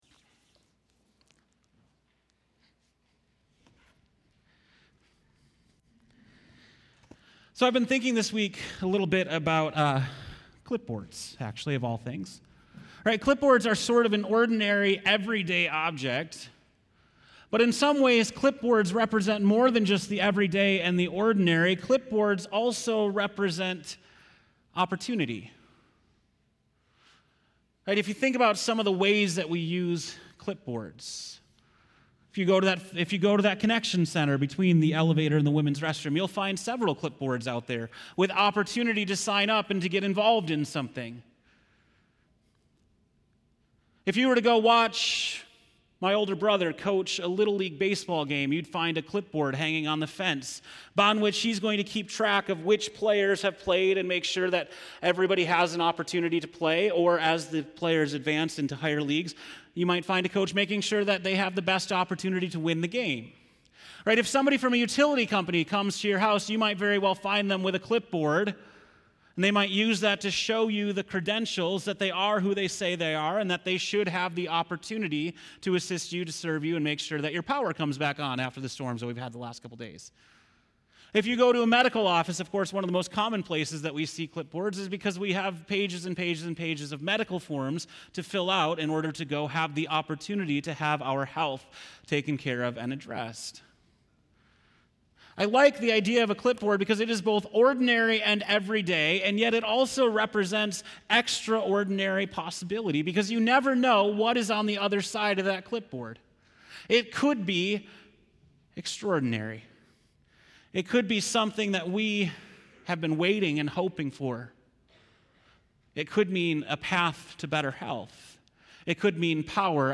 September 2, 2018 (Morning Worship)